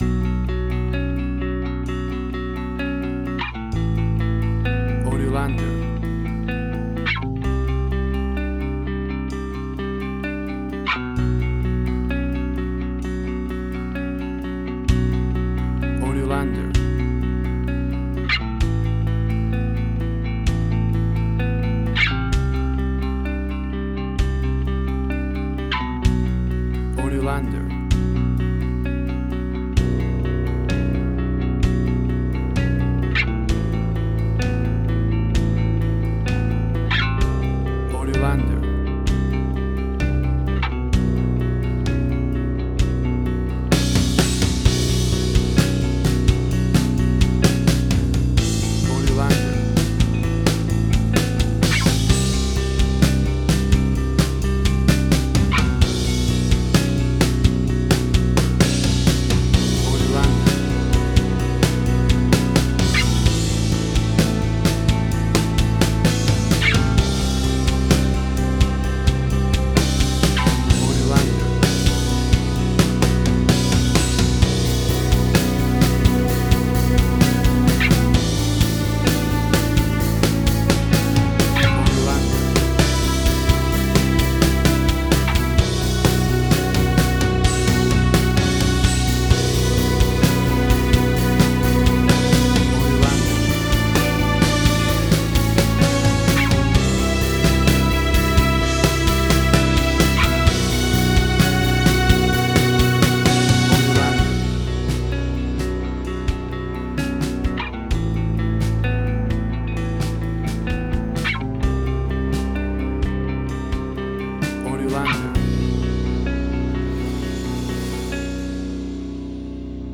Post-Rock.
Tempo (BPM): 129